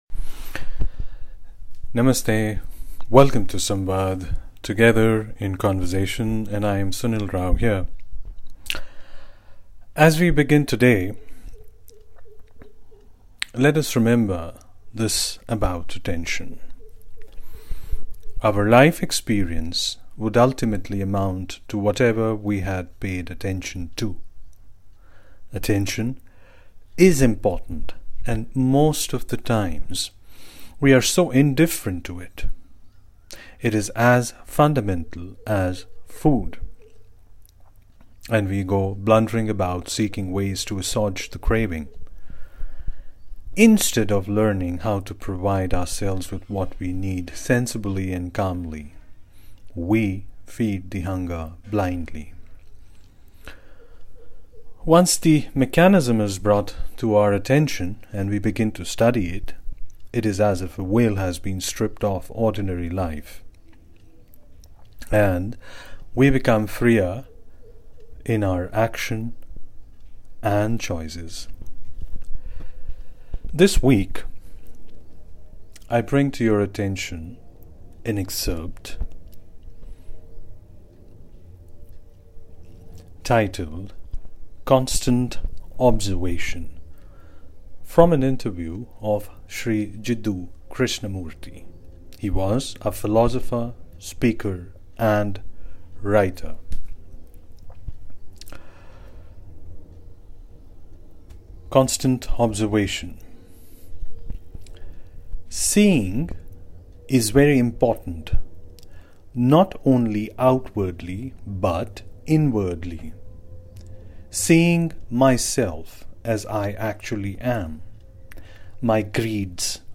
An interview with Jiddu Krishnamurti (Excerpt)